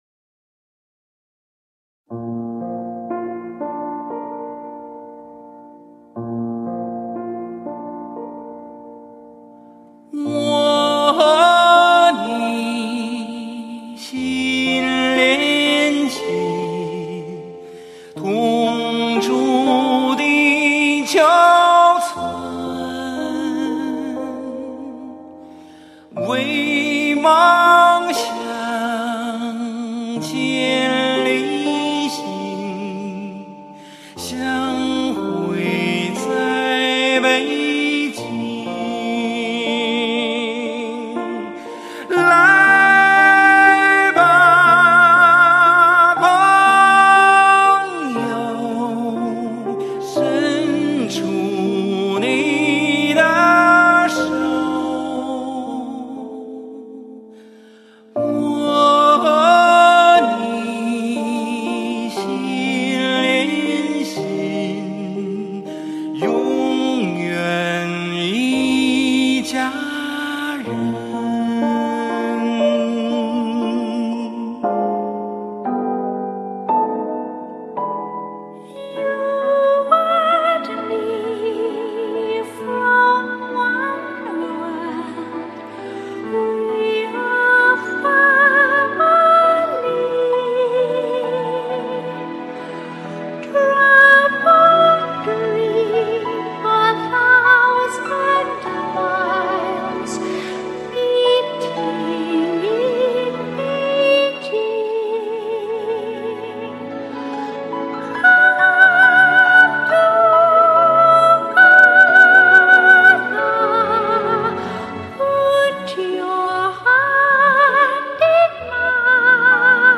Genre: Crossover